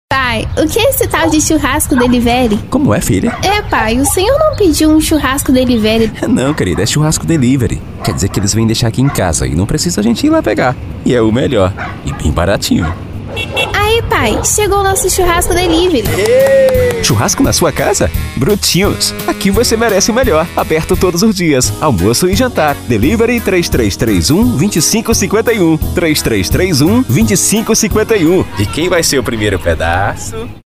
Spot - Coloquial/Conversado: